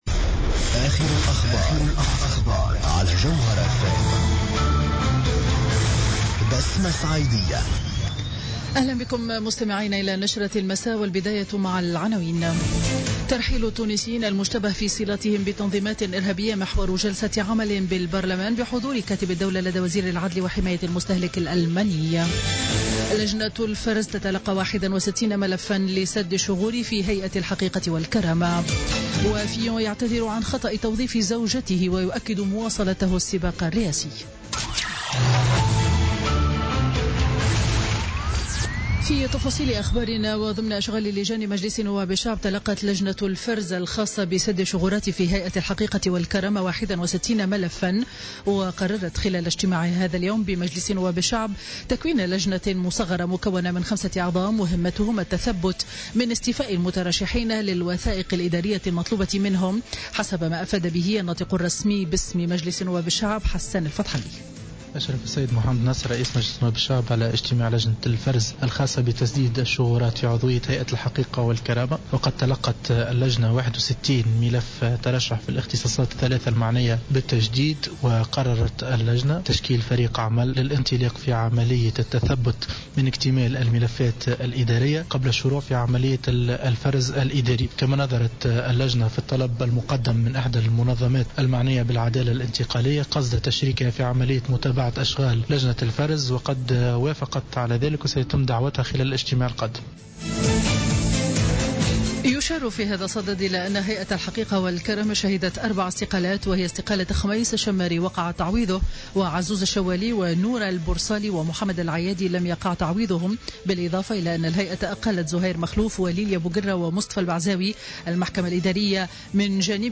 نشرة أخبار السابعة مساء ليوم الاثنين 6 فيفري 2017